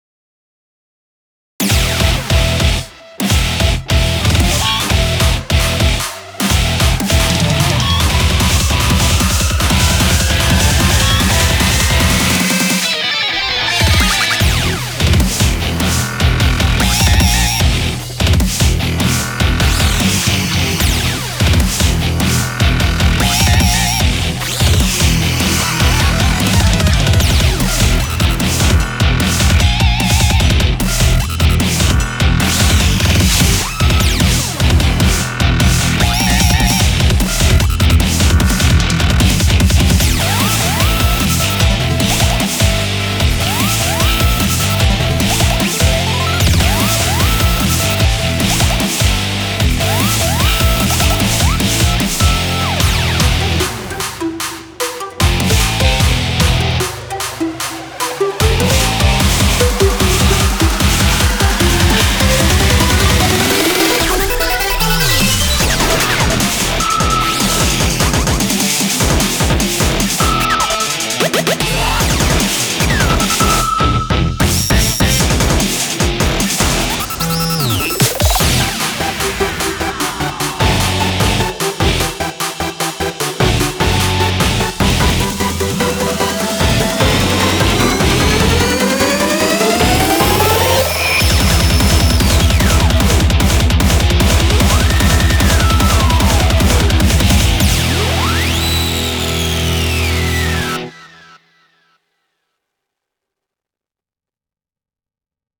BPM75-300
Audio QualityMusic Cut